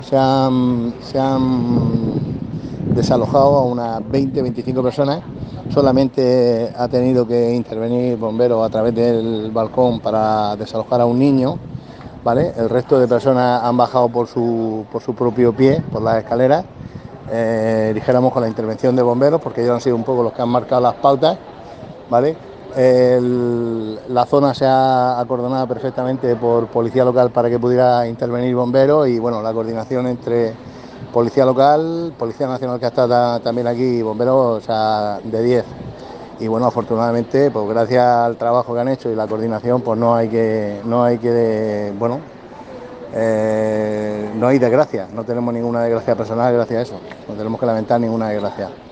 Enlace a Declaraciones del edil de Seguridad Ciudadana, José Ramón Llorca.